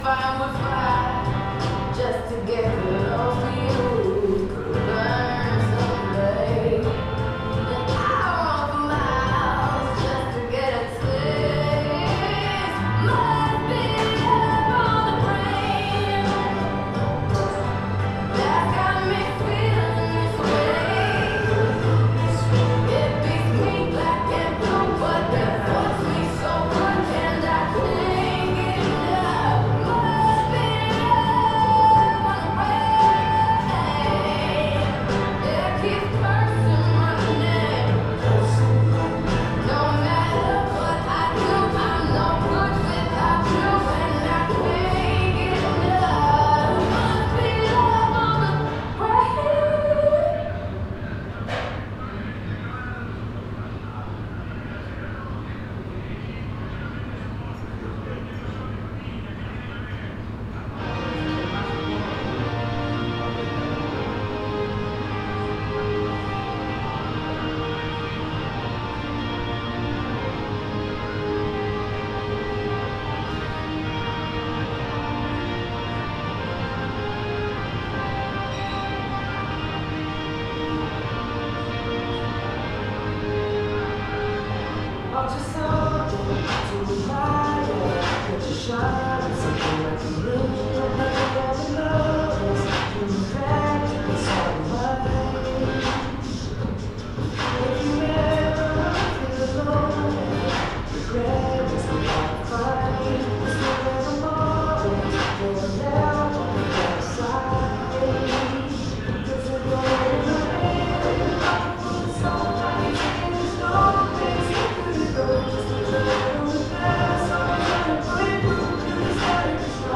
Terminal de ómnibus 13.10 hs. 09 de Mayo 2024
esf-elortondo-terminal-de-omnibus.mp3